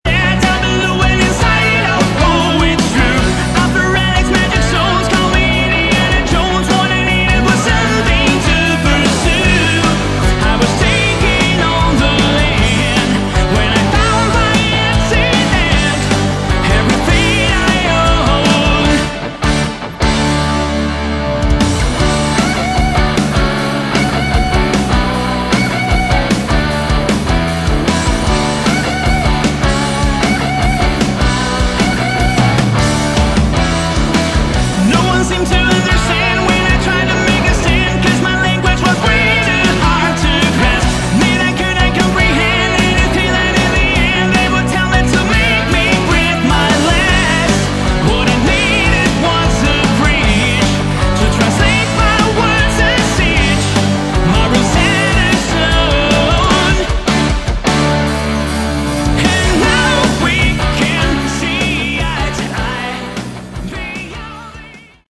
Category: AOR / Melodic Rock
vocals
guitars
keyboards
bass
drums